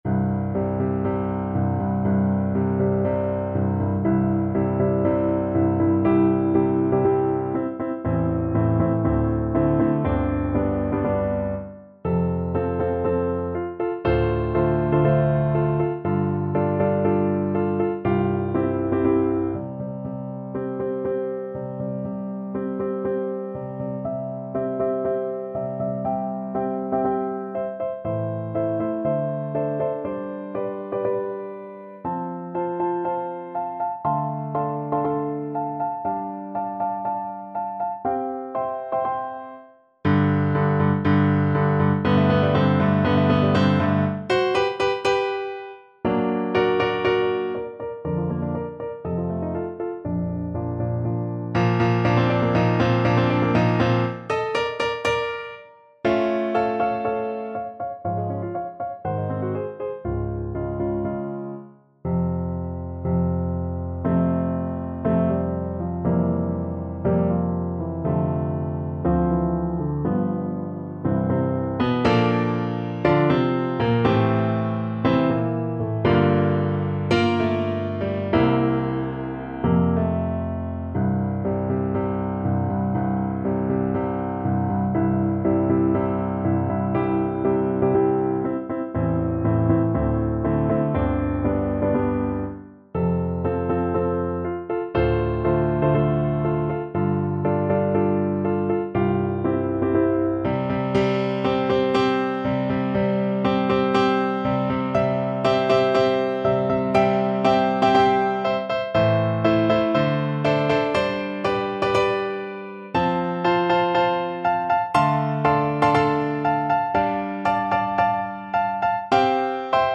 Play (or use space bar on your keyboard) Pause Music Playalong - Piano Accompaniment Playalong Band Accompaniment not yet available reset tempo print settings full screen
C major (Sounding Pitch) G major (French Horn in F) (View more C major Music for French Horn )
~ = 120 Tempo di Marcia un poco vivace
4/4 (View more 4/4 Music)
Classical (View more Classical French Horn Music)